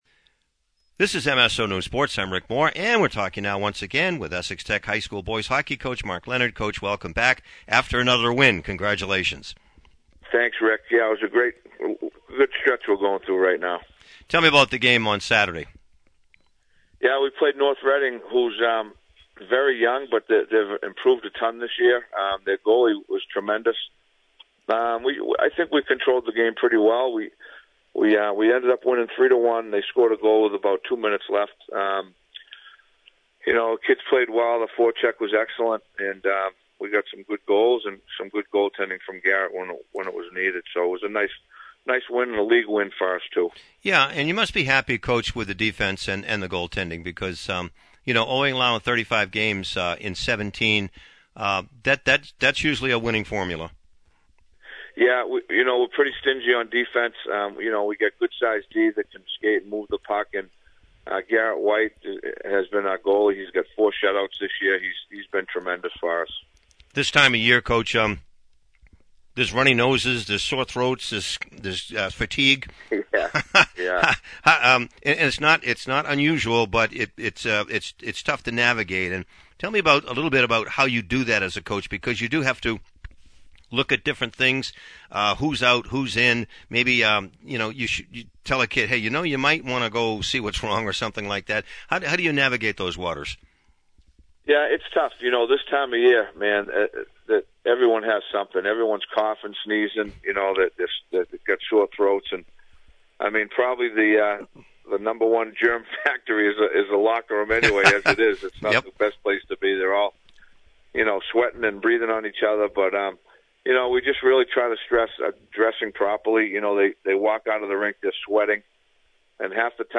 Post-game, Pre-game